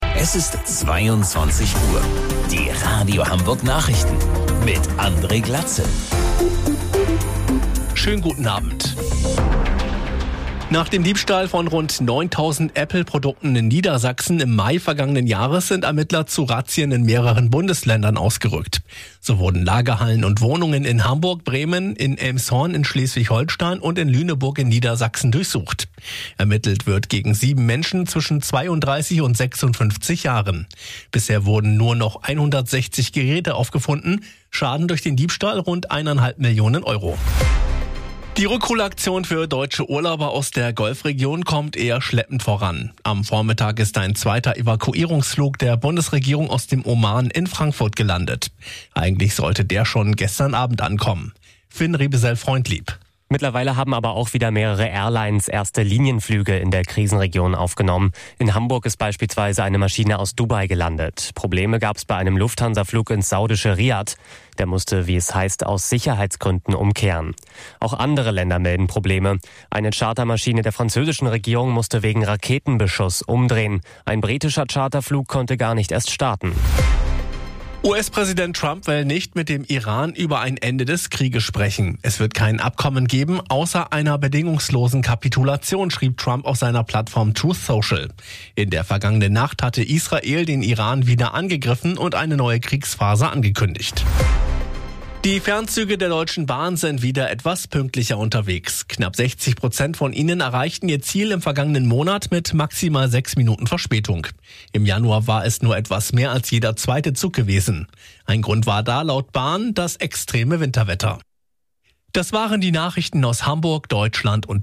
Radio Hamburg Nachrichten vom 06.03.2026 um 22 Uhr